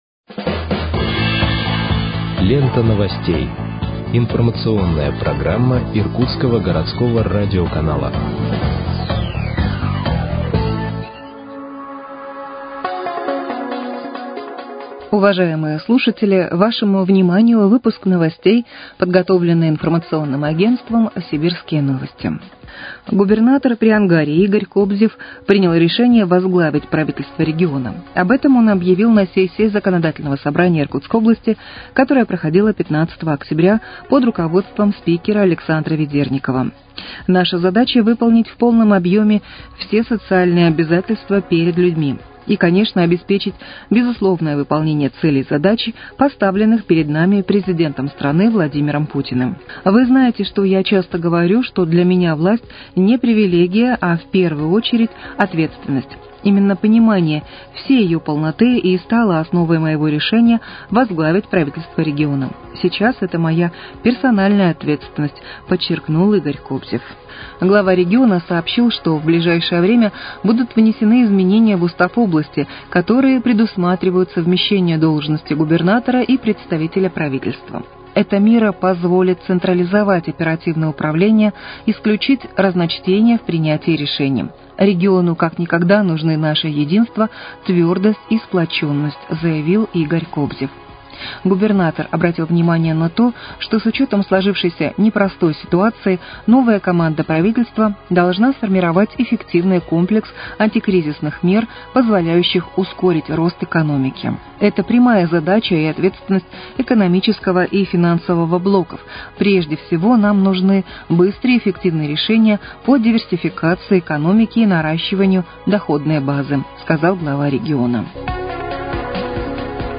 Выпуск новостей в подкастах газеты «Иркутск» от 17.10.2025 № 1